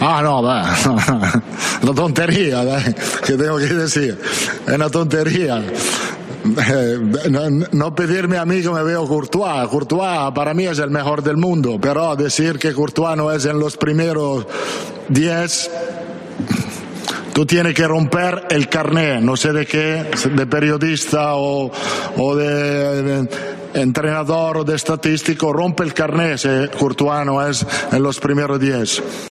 Carlo Ancelotti fue preguntado por este hecho en la rueda de prensa previa al Mallorca - Real Madrid y posterior a la remontada en Champions ante el PSG, en la que Courtois tuvo una actuación bastante notable.